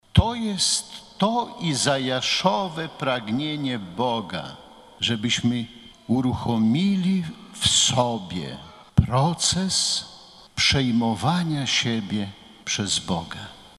Z tej okazji bp warszawsko-praski przewodniczył uroczystej mszy św. w katedrze św. Michała Archanioła i św. Floriana Męczennika.